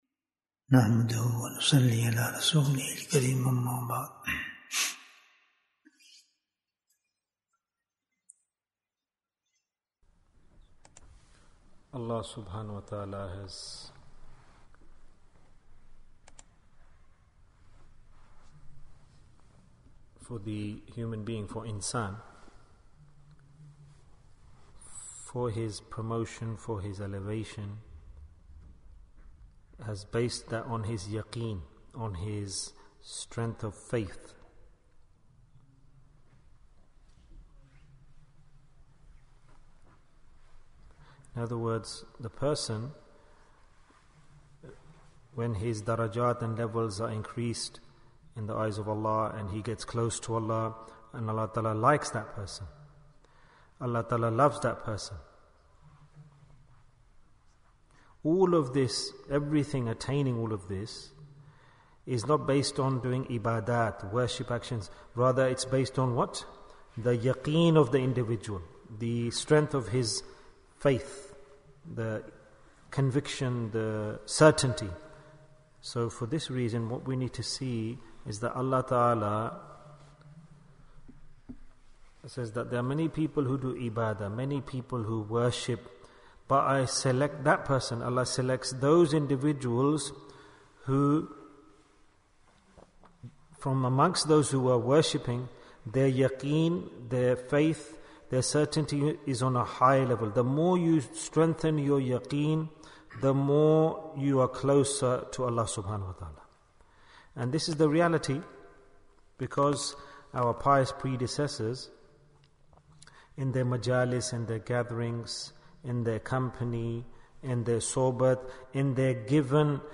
What is the Biggest Deed for the Last Asharah? Bayan, 48 minutes14th April, 2023